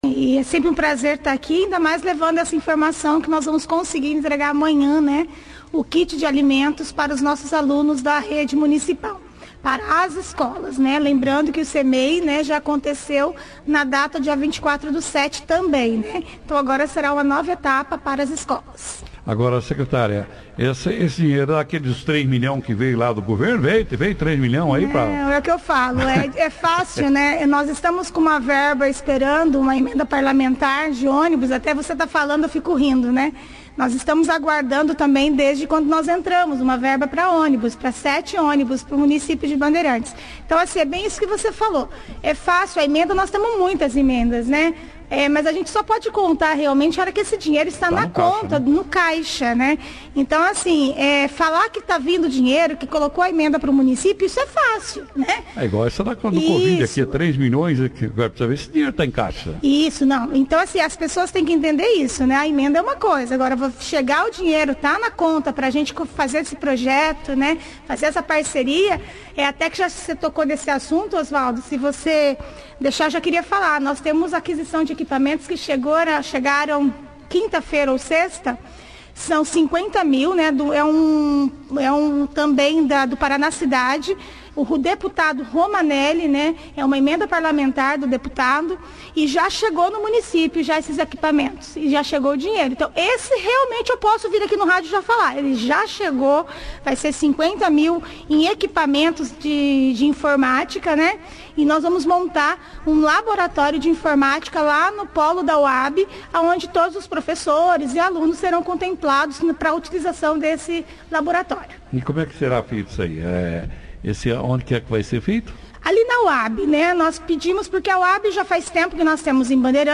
A Secretária de Educação de Bandeirantes, Valquiria Martins, (Foto), participou da 2ª edição do jornal operação Cidade desta quarta-feira, 08/07, falando detalhes da distribuição de kits de alimentação nestes dias 08 e 09 a todos alunos das escolas municipais.